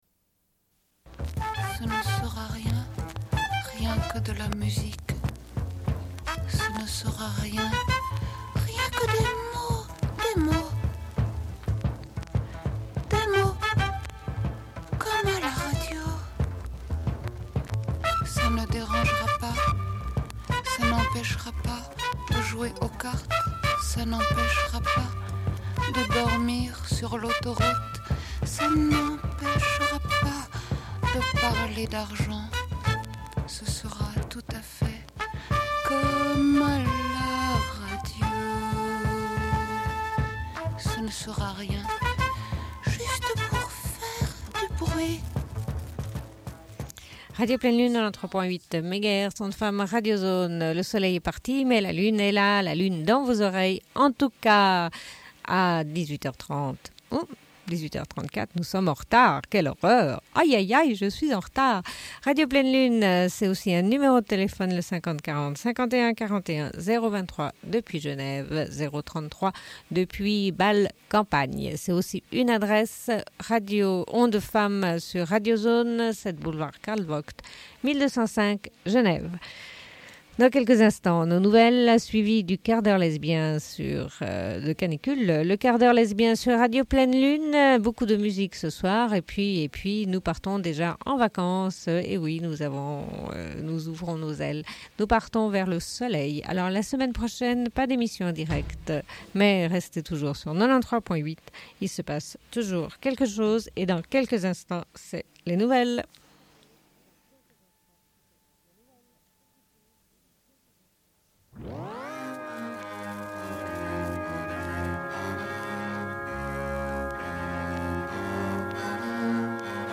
Bulletin d'information de Radio Pleine Lune du 15.04.1992 - Archives contestataires
Une cassette audio, face A00:30:23